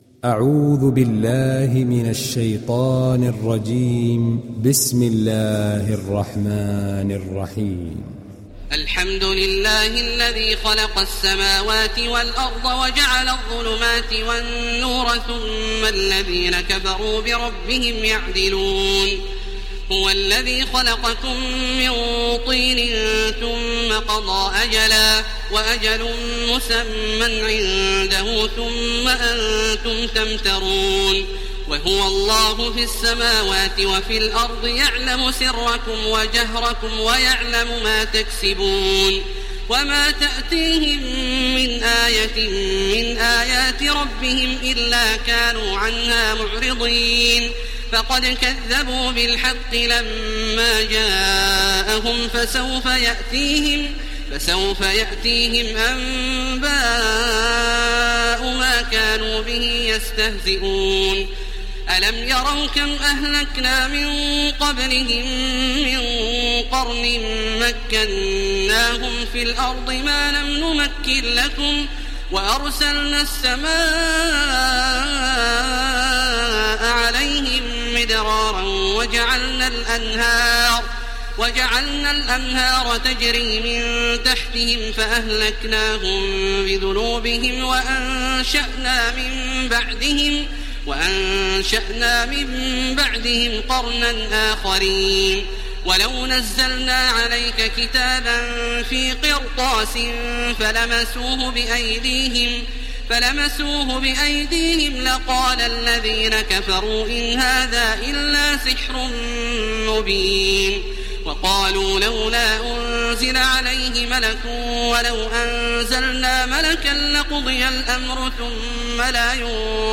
ডাউনলোড সূরা আল-আন‘আম Taraweeh Makkah 1430